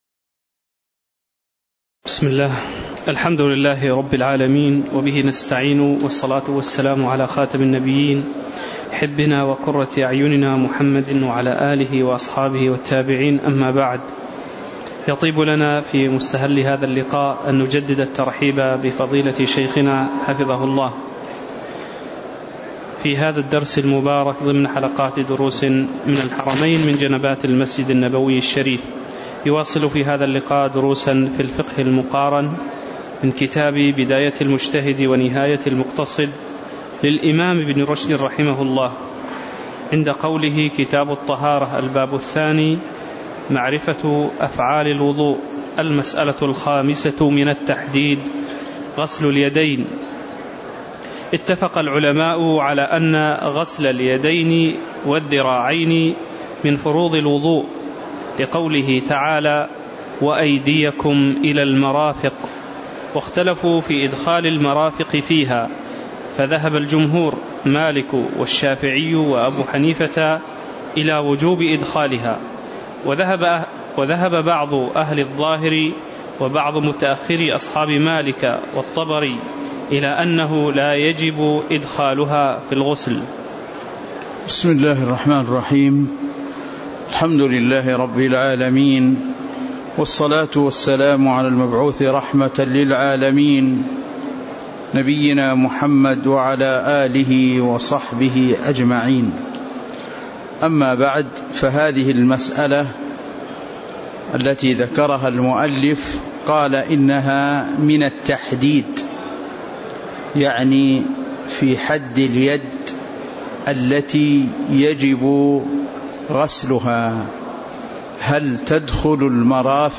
تاريخ النشر ١٤ جمادى الآخرة ١٤٣٩ هـ المكان: المسجد النبوي الشيخ